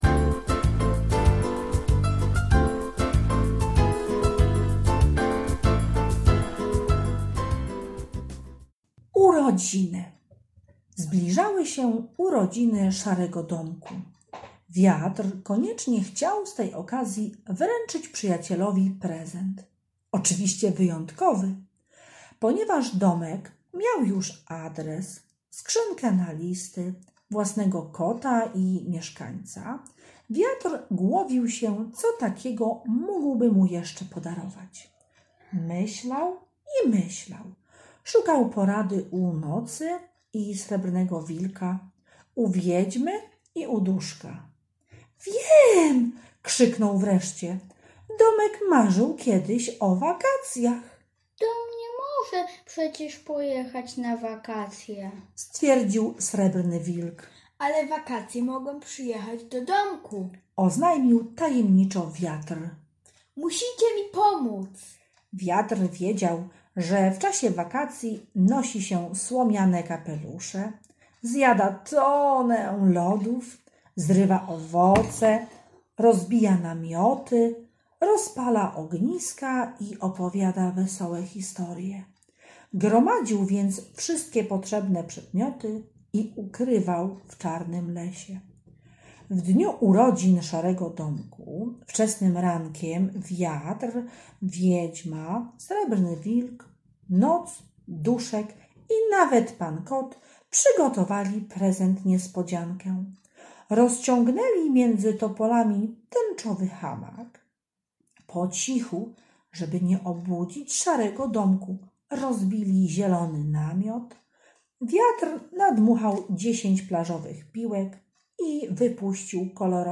Ostatni IX rozdział audiobooka „Urodziny”